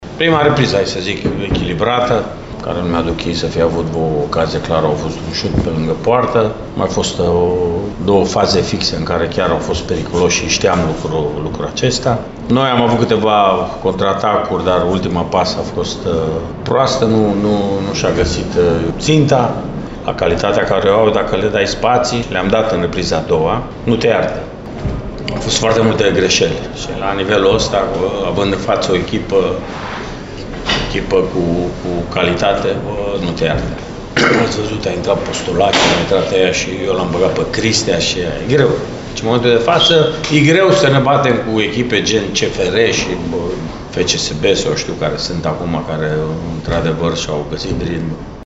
În schimb, Mircea Rednic a venit resemnat la sesiunea de declarații, menționând că în momentul de față e greu ca Bătrâna Doamnă să joace de la egal la egal cu fruntașele campionatului intern: